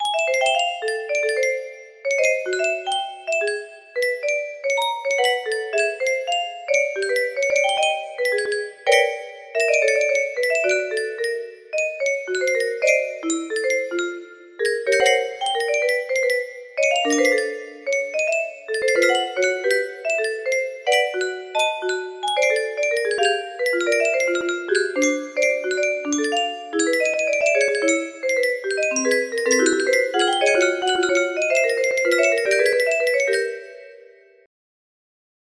random idk music box melody